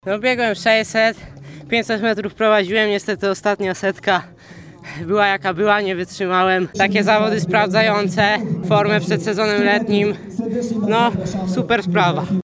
zawodnik-mityng23.mp3